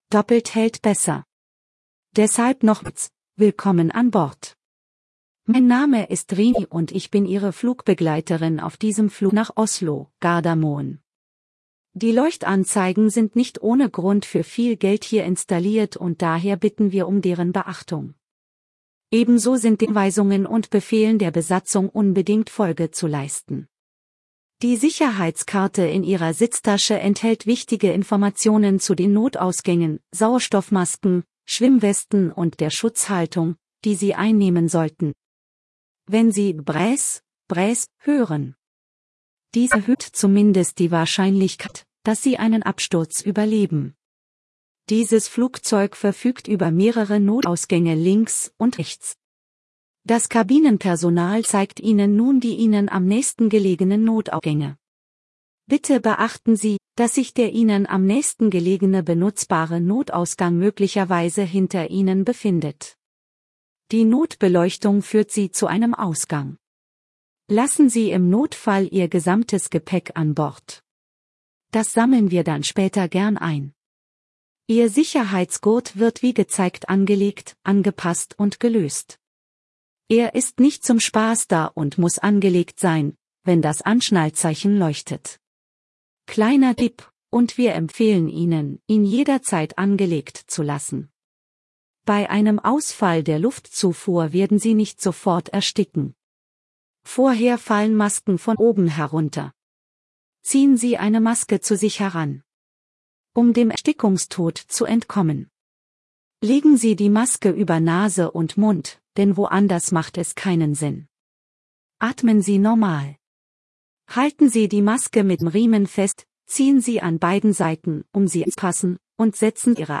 SafetyBriefing.ogg